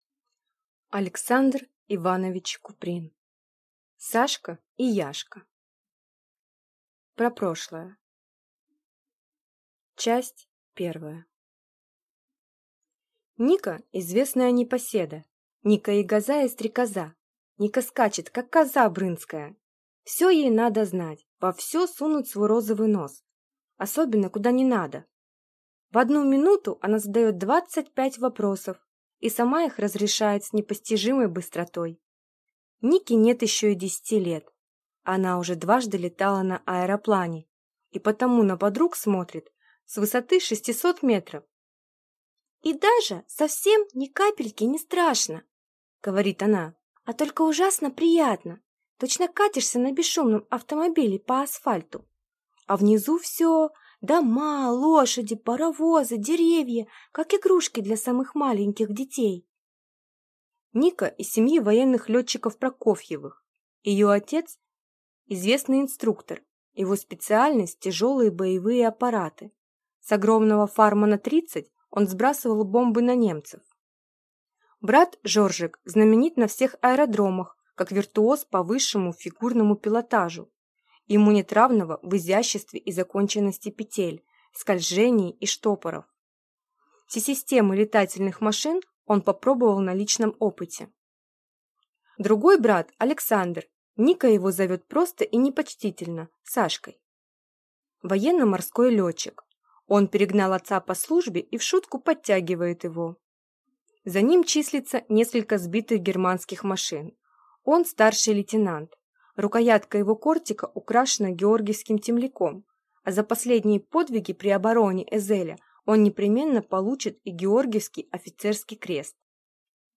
Аудиокнига Сашка и Яшка | Библиотека аудиокниг